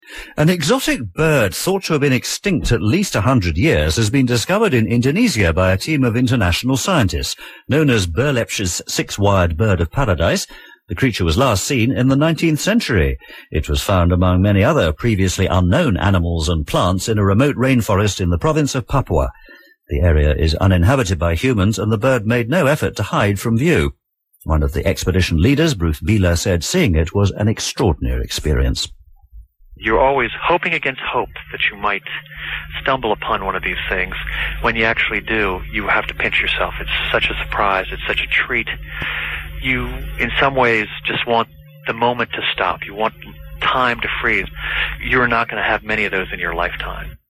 exotic_bird.mp3